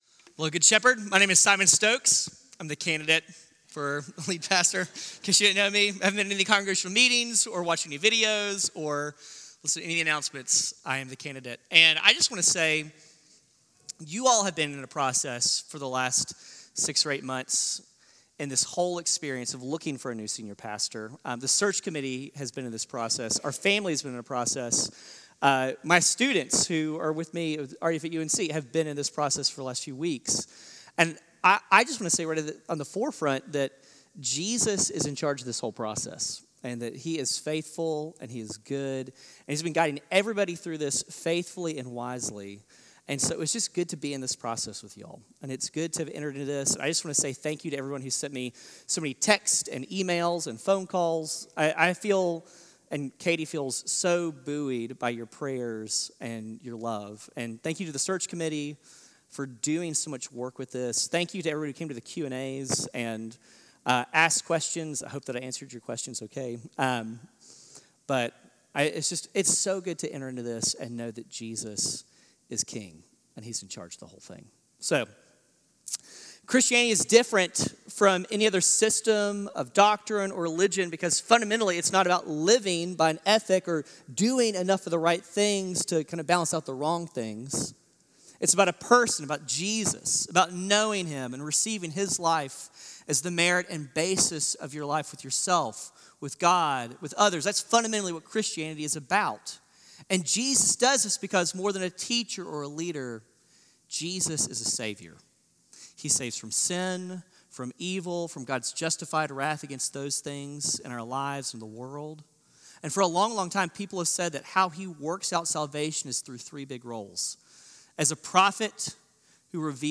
CGS-Service-4-28-24.mp3